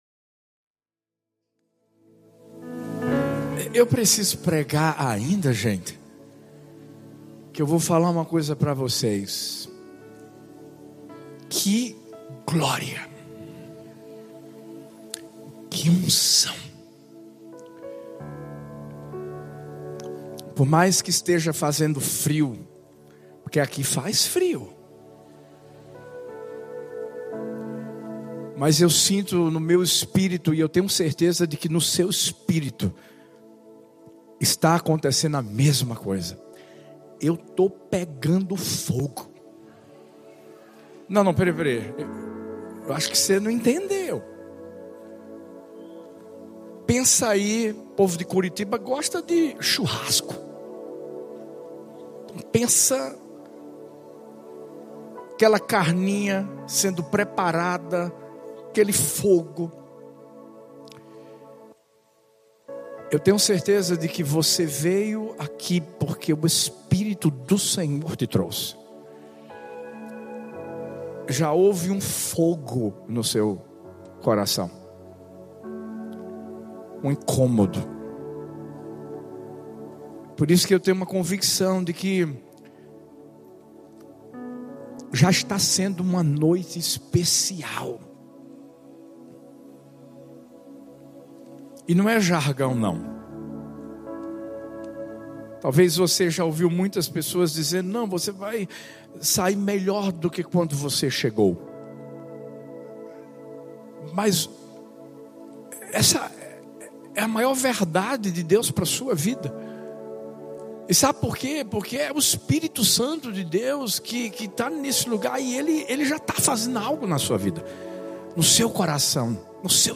Culto ONE